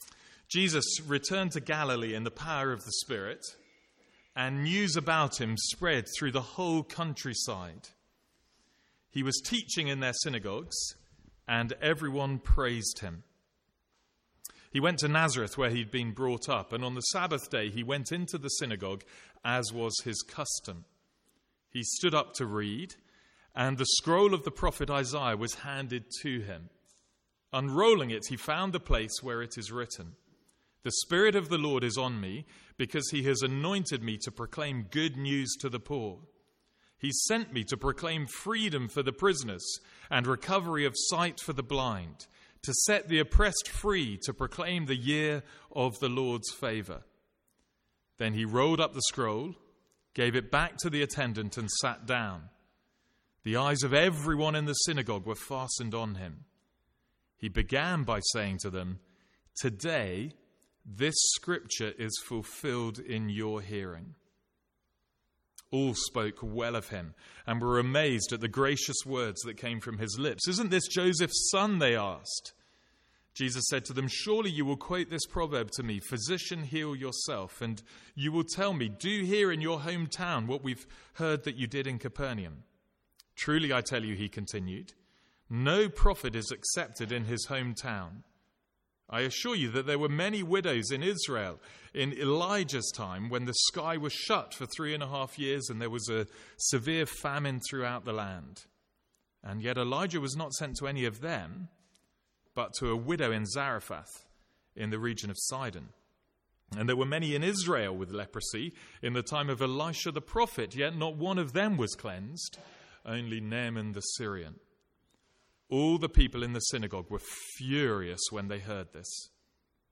From the Sunday morning series in Luke.